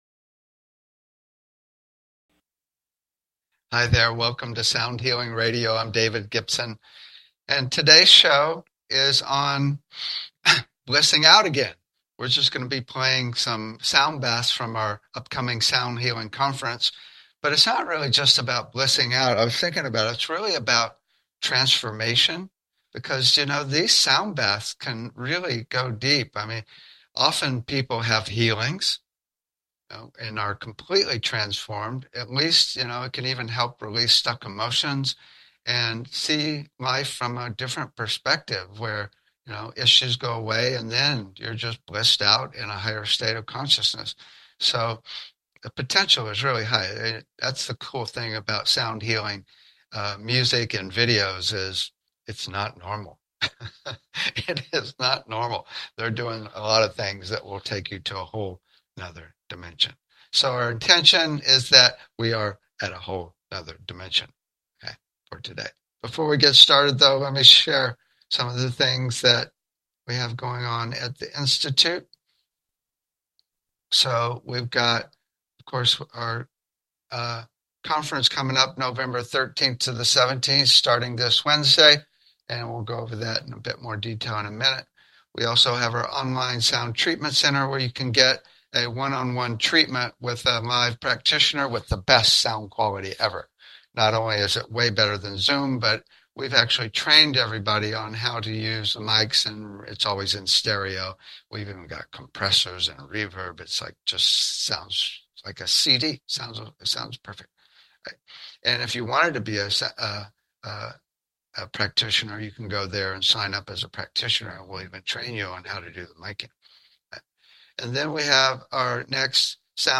Talk Show Episode
The show is a sound combination of discussion and experience including the following topics: Toning, Chanting and Overtone Singing - Root Frequency Entrainment - Sound to Improve Learning -Disabilities - Using Sound to Connect to Spirit - Tuning Fork Treatments - Voice Analysis Technologies - Chakra Balancing - Sound to Induce Desired States of Being - Tibetan/Crystal Bowl Massage - Electronic Nerve Stimulation - Water Sound Infusion Systems - Sound Visualization systems - Infratonics Holographic Sound - Scalar wave EESystem Drumming and Rhythm - HydroAcoustic Therapy - Neurophone Bio-Tuning - Sound Surgery - Cymatics Frequency based therapeutic devices - VibroAcoustic Sound Chairs and Tables /soundhealing#archives /soundhealing#showposts /soundhealing#upcoming /customshow/2574 /customshow/mrss/2574 /soundhealing#feedback BBS Station 1 Bi-Weekly Show -e- 7:00 pm CT 7:55 pm CT Saturday Education Energy Healing Sound Healing Love & Relationships Emotional Health and Freedom Mental Health Science Self Help Spiritual 0 Following Login to follow this talk show Sound Healing Log in or register to post comments